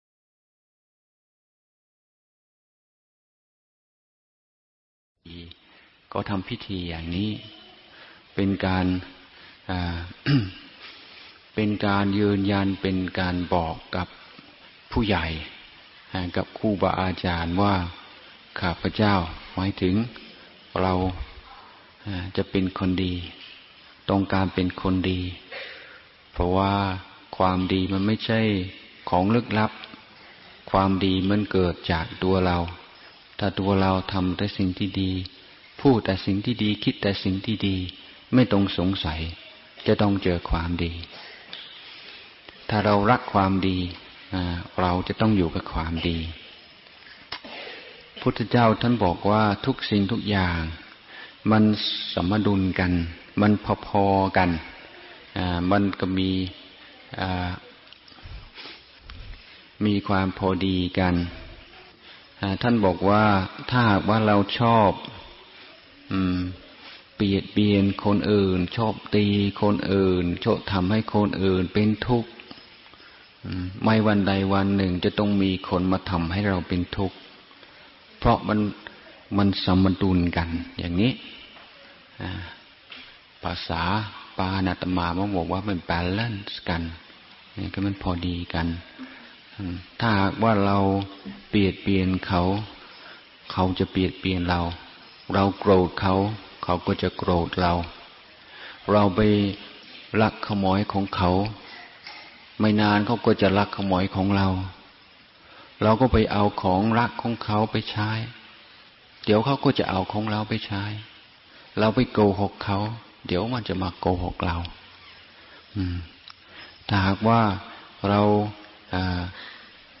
พระพรหมพัชรญาณมุนี (ฌอน ชยสาโร) - งานวันเด็ก
ฟังธรรมะ Podcasts กับ พระธรรมพัชรญาณมุนี (ฌอน ชยสาโร)